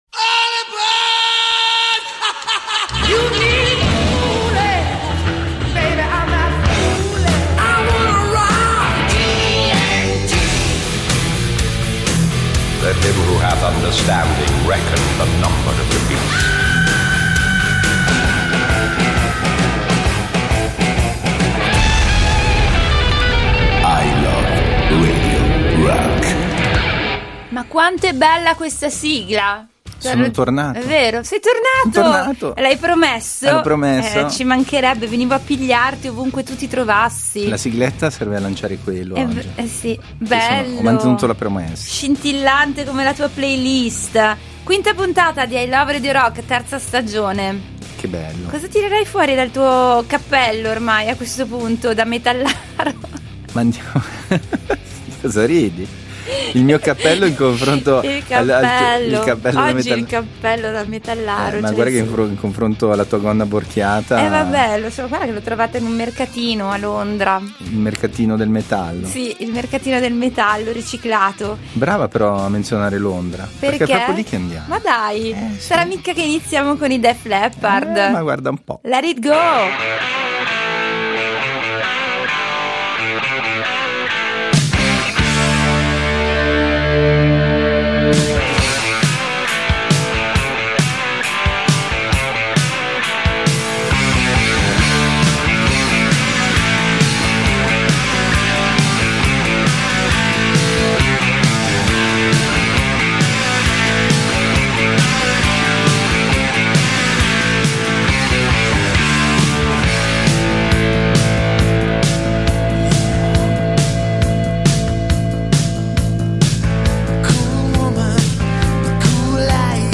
il programma dedicato alle sonorità hard rock e heavy metal che hanno fatto la storia. https